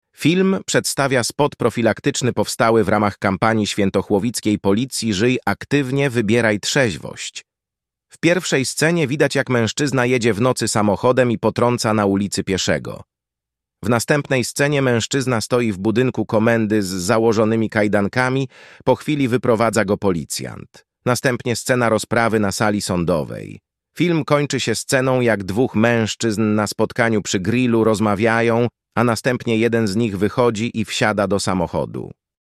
Nagranie audio audiodyskrypcja.mp3